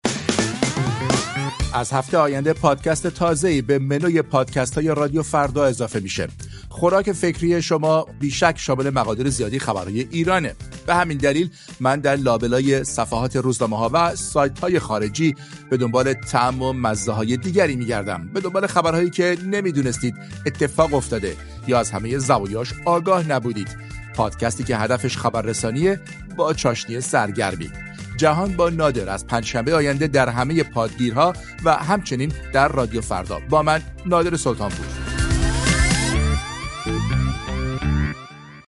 با زبانی خودمونی همراه با موسیقی